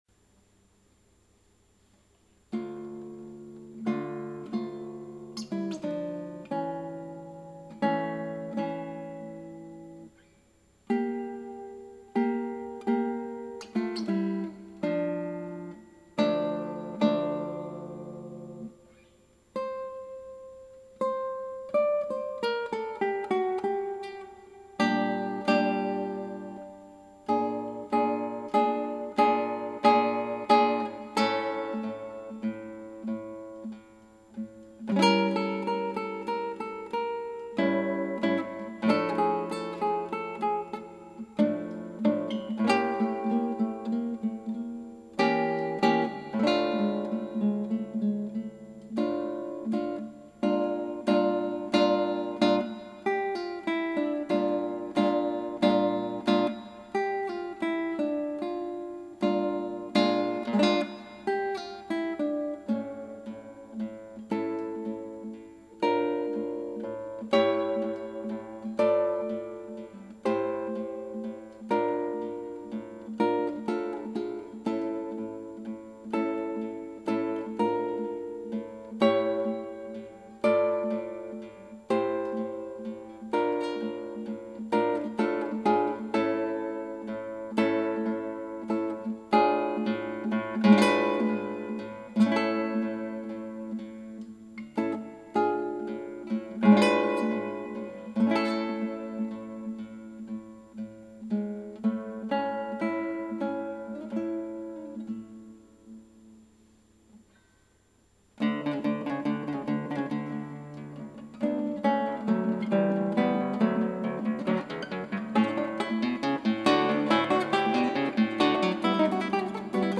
クラシックギター　ストリーミング　コンサート
楽譜見ながらの演奏なんで、途中譜面めくってる間があります。なんか速くて難しいトコ全部失敗してるような・・・・。
これはどう贔屓目に見ても練習途中という出来ですね・・・というかその通りなんだけどさ。